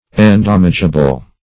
Search Result for " endamageable" : The Collaborative International Dictionary of English v.0.48: Endamageable \En*dam"age*a*ble\, a. Capable of being damaged, or injured; damageable.